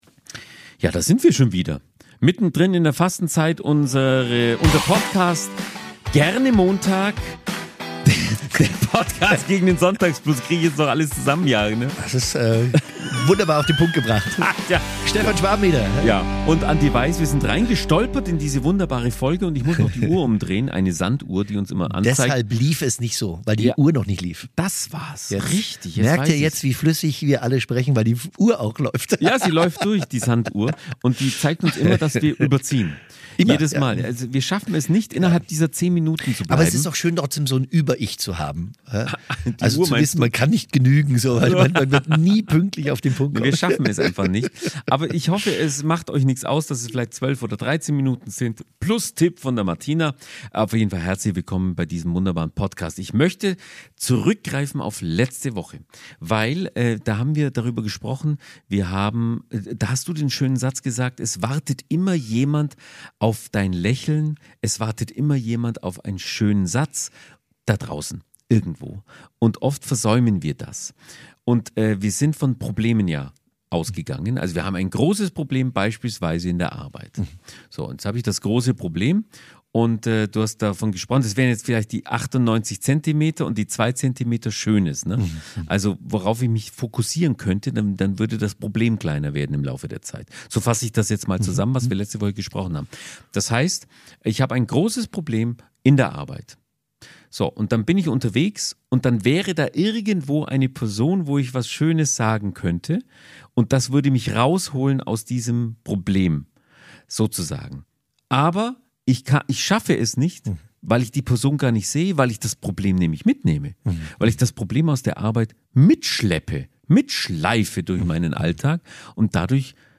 Heute wird viel gelacht – unabsichtlich!